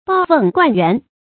抱甕灌園 注音： ㄅㄠˋ ㄨㄥˋ ㄍㄨㄢˋ ㄧㄨㄢˊ 讀音讀法： 意思解釋： 傳說孔子的學生子貢，在游楚返晉過漢陰，見一位老人抱著水甕去灌園，就建議他用機械汲水，老人不愿意。